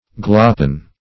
Search Result for " gloppen" : The Collaborative International Dictionary of English v.0.48: Gloppen \Glop"pen\ (gl[o^]p"pen), v. t. & i. [OE. glopnen to be frightened, frighten: cf. Icel. gl[=u]pna to look downcast.]